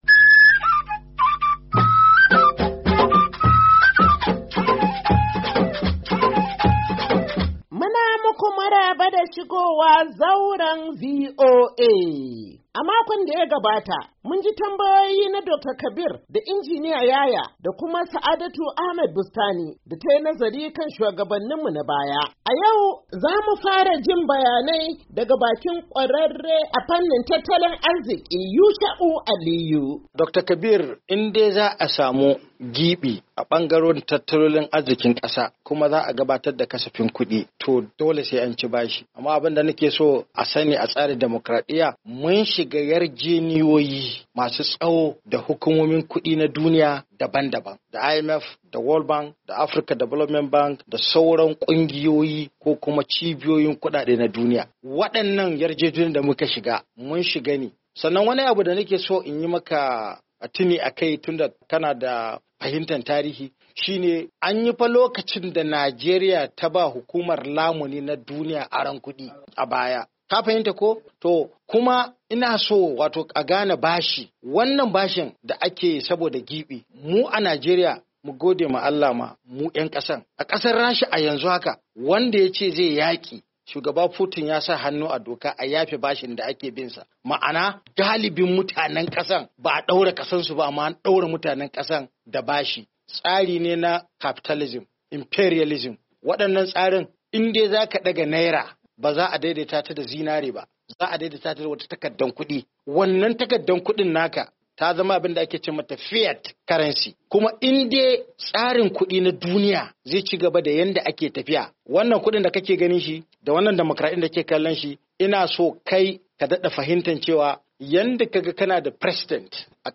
Shirin Zauran VOA na wannan makon, ya ci gaba da kawo muku muhawara kan yadda Najeriya ke ci gaba da karbo bashi, wanda zai barwa 'ya'ya da jikoki tabon da ba za su iya maganinsa ba.